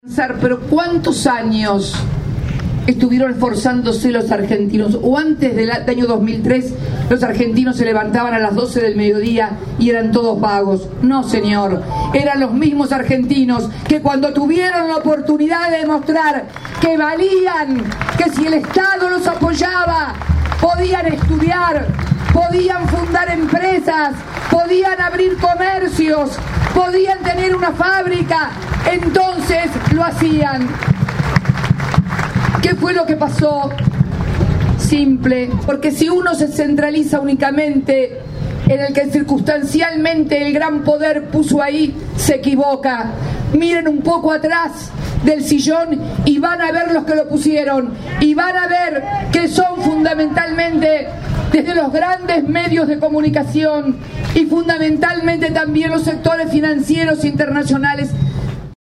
Cristina Fernández visitó Ensenada
A continuación la ex mandataria Cristina Fernández se dirigió a la multitud y contó que ella recordaba otra Ensenada, «me acordaba de una Ensenada que no tiene nada que ver con esta Ensenada de hoy, pujante, creciente, es el producto también de políticas públicas, la reincorporación de YPF para el patrimonio de la nación», afirmó.